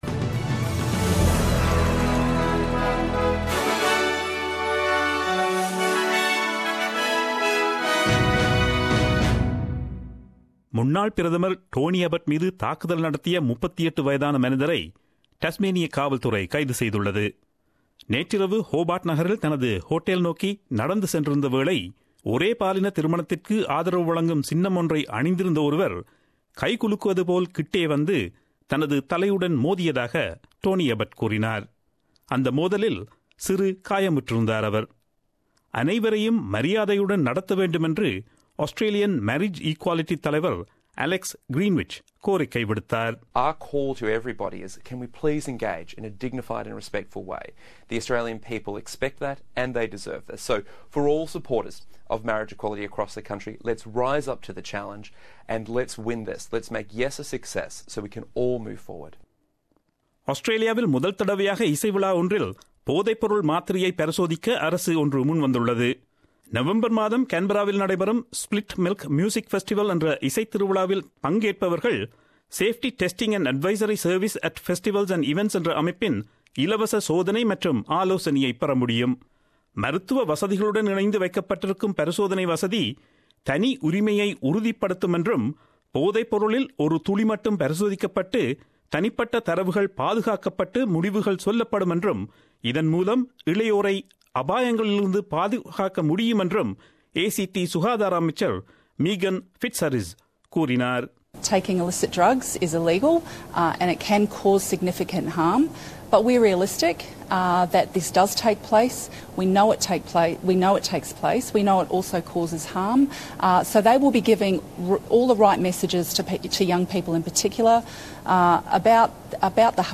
Australian news bulletin aired on Friday 22 September 2017 at 8pm.